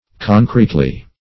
concretely.mp3